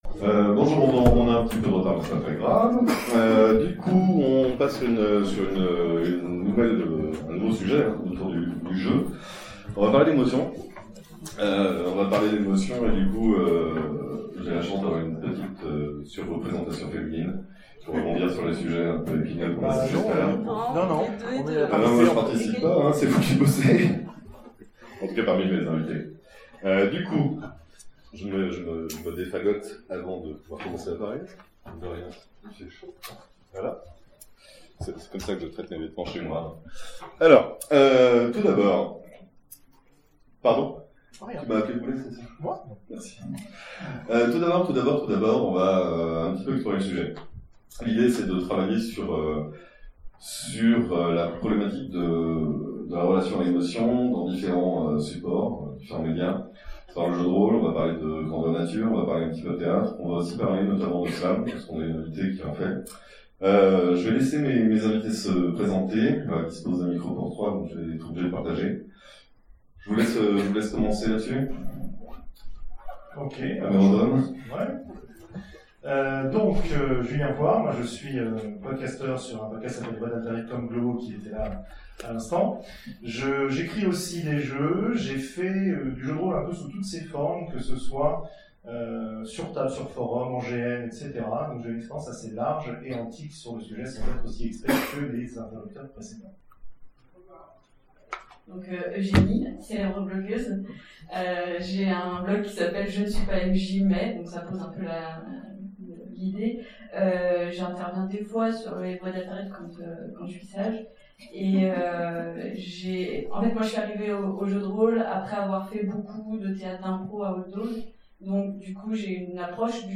Utopiales 2017 : Conférence JDR/GN/Théâtre : Jouer avec les émotions
Conférence JDR/GN/Théâtre